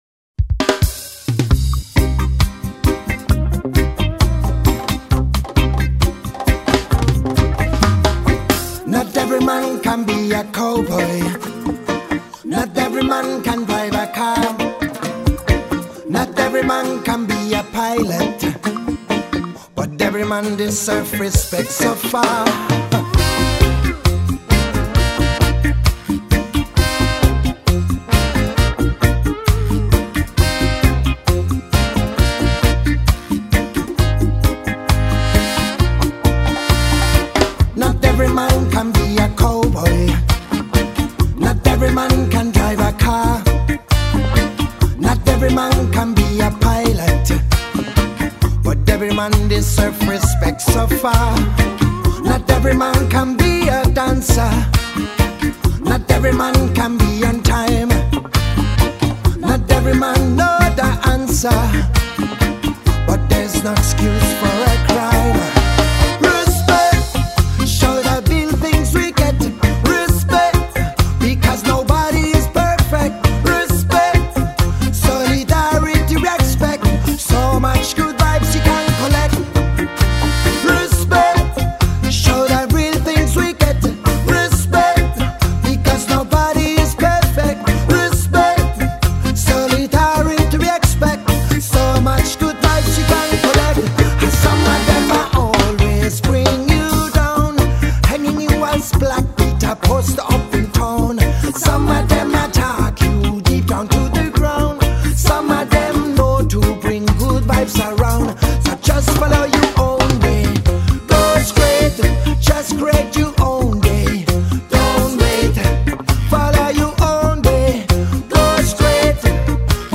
Reggae / Funk / Electro.